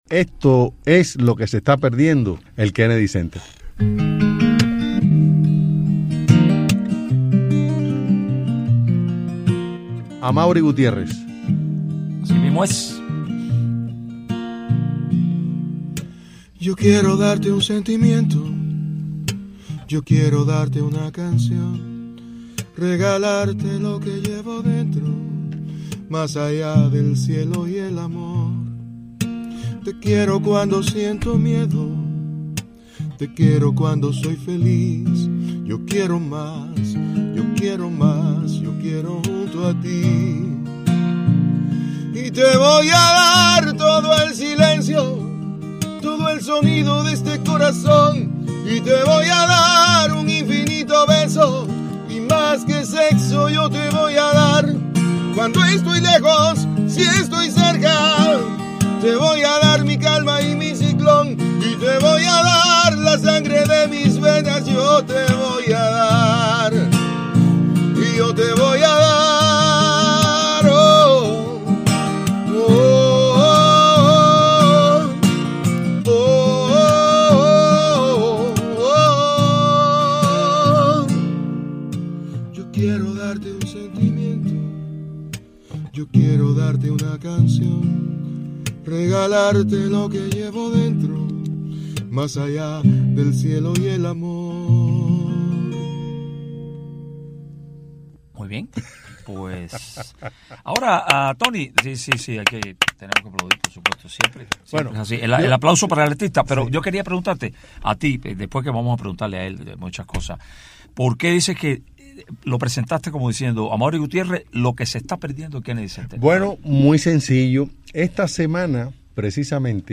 Entrevista al cantautor cubano Amaury Gutiérrez